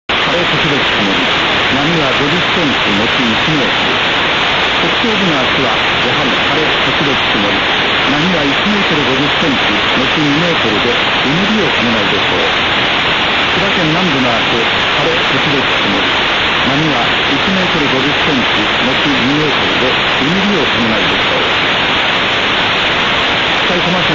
2.　　ノイズ　（Function Generator: whitenoise, 10KHz BW)
Original 音源と共にファンクションジェネレータからホワイトノイズを加えます。
MIC IN (L:音源、　R:Noise）
Noise_ON.WMA